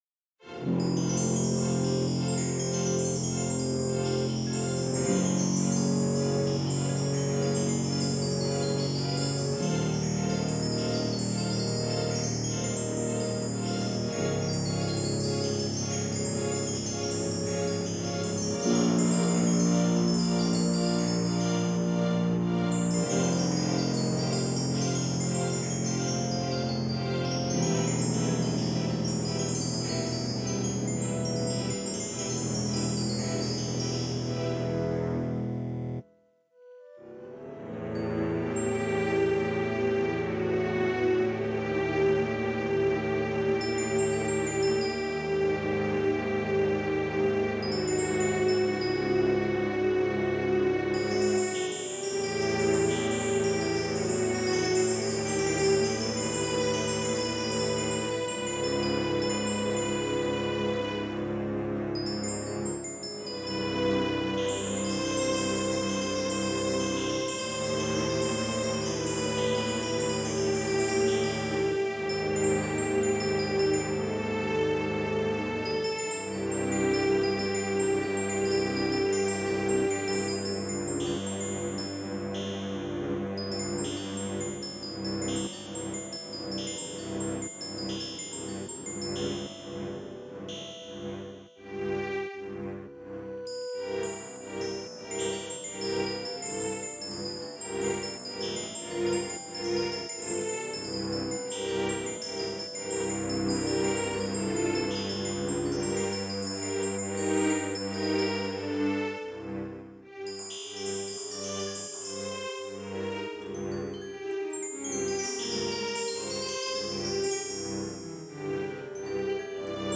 Another BGM Music
Several Sound Effects